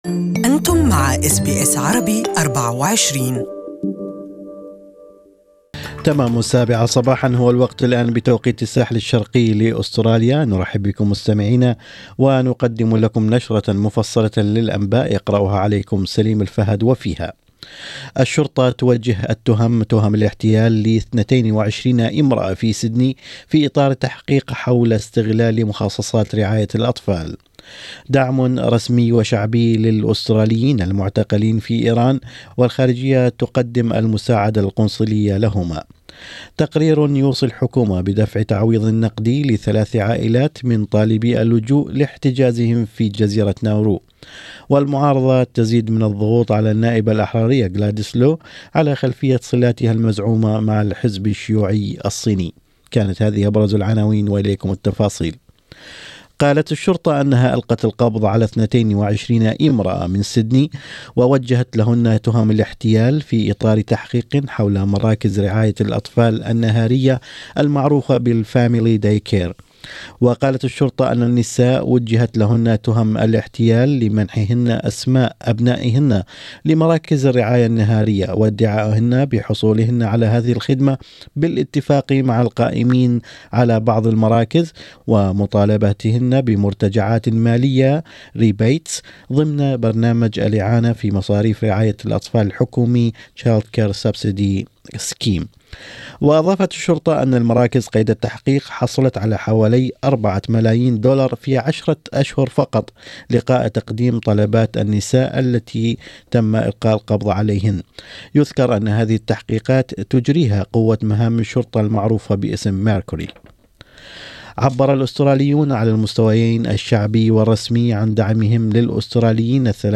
Morning News: 22 Sydney women charged with family day care fraud